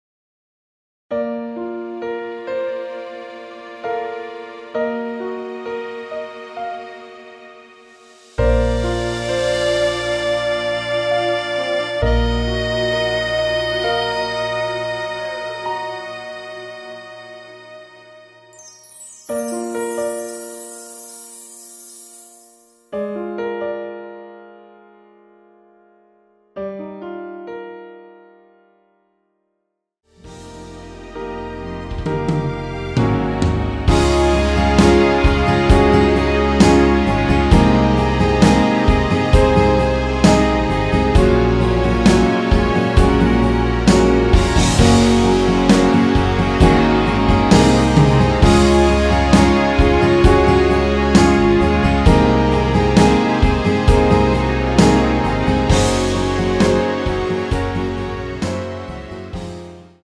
MR 입니다.
Bb
앞부분30초, 뒷부분30초씩 편집해서 올려 드리고 있습니다.